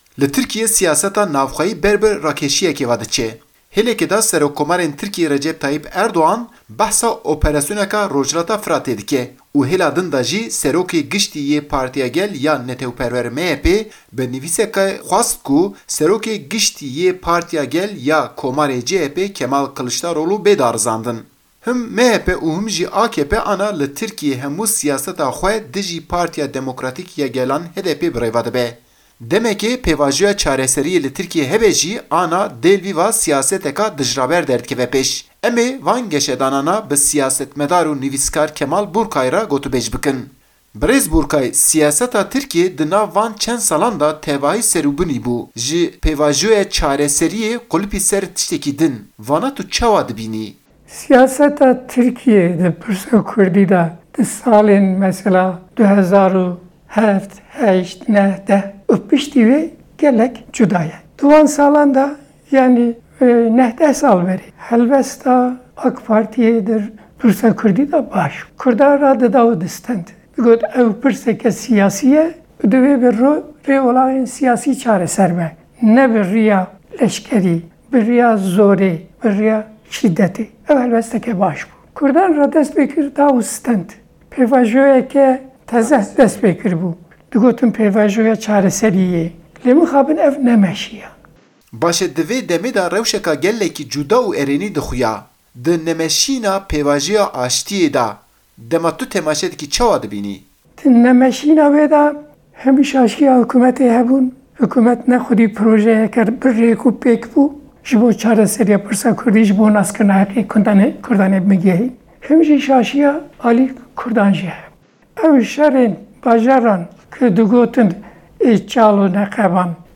Raporta Deng